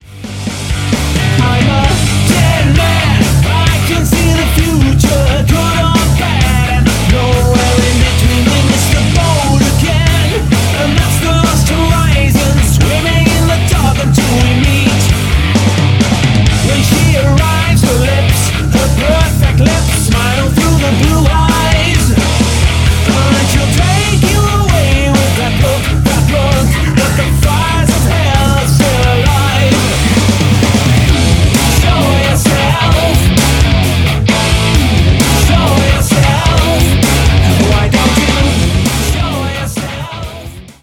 RETRO MODERNIST ALT-METAL